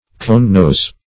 conenose \cone"nose\, cone-nose \cone"-nose`\, n.